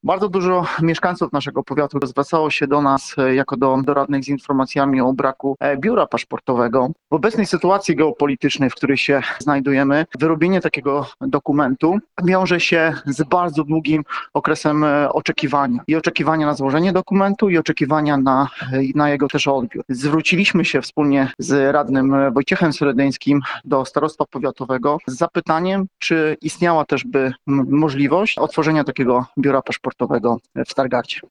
Mówi stargardzki radny Grzegorz Rybaczuk.